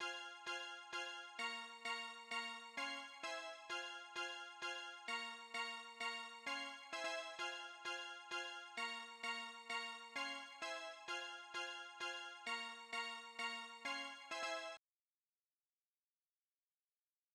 motorsport_bright bell.wav